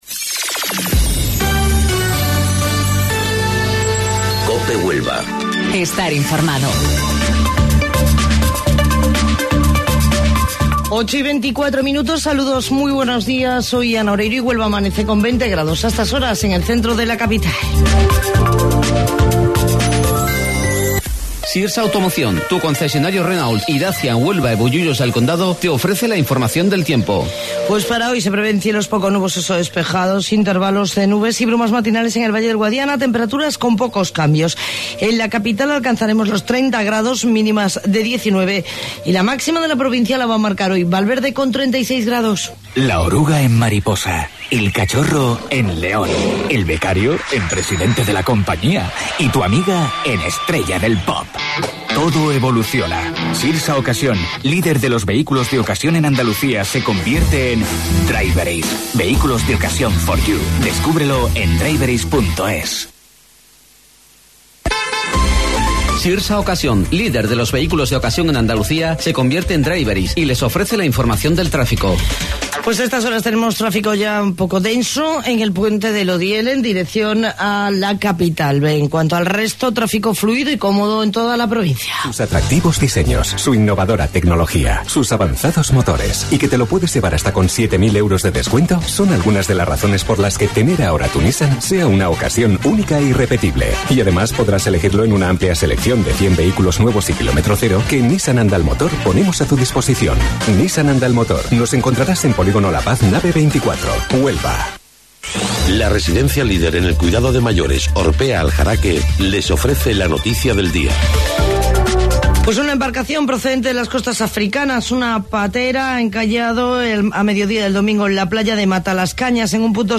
AUDIO: Informativo Local 08:25 del 15 de Julio